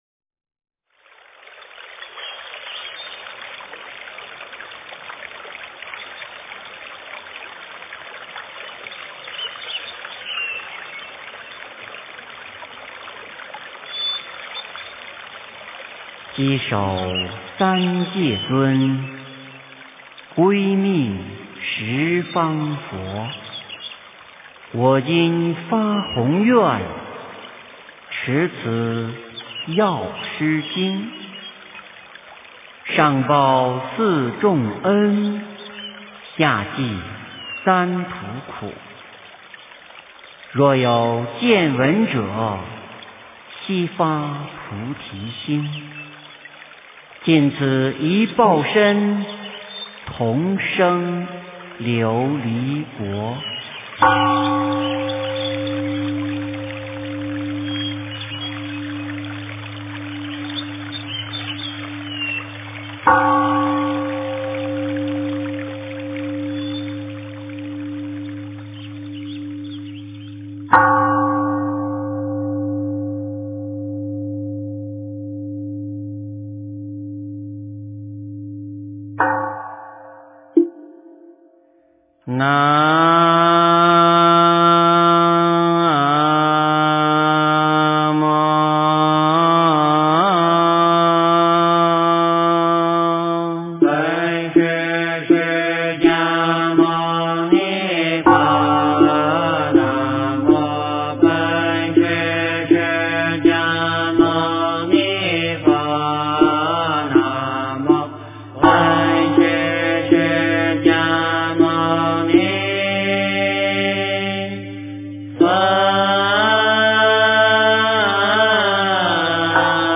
药师琉璃光如来本愿功德经 - 诵经 - 云佛论坛